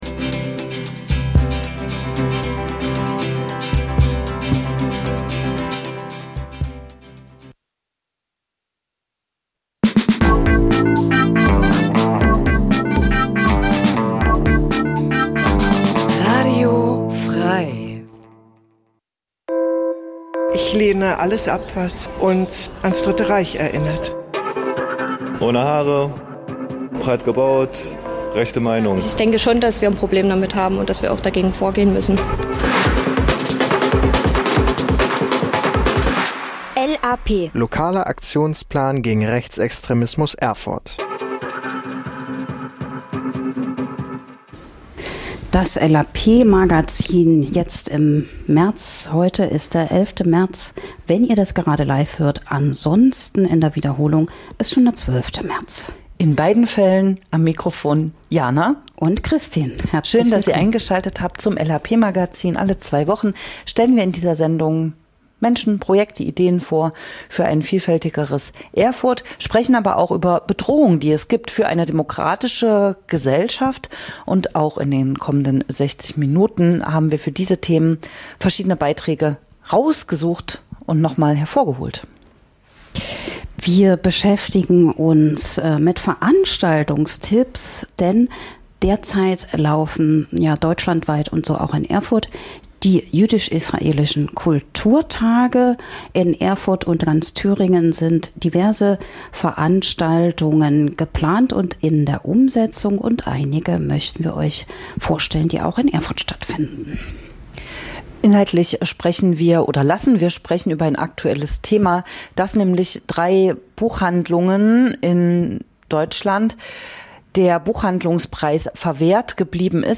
- Workshopangebot bei der Naturfreundejugend Erfurt Interview zu den Angeboten Infos * bis 26.